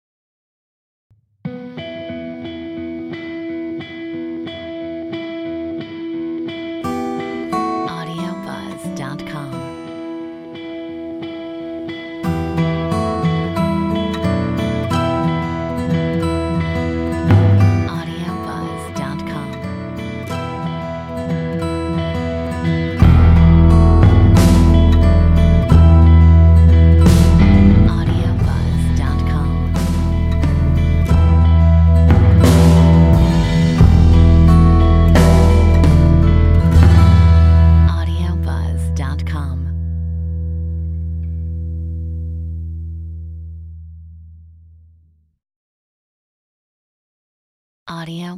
Metronome 89